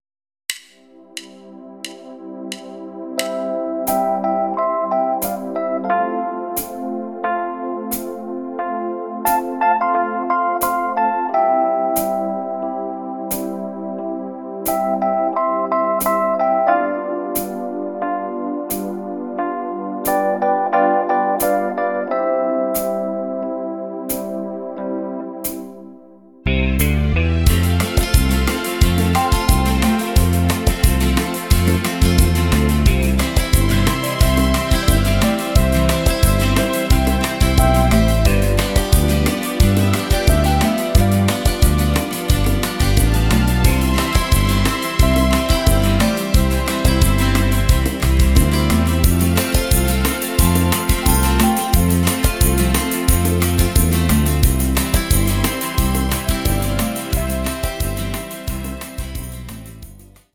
sehr schöner Oldie mit Niveau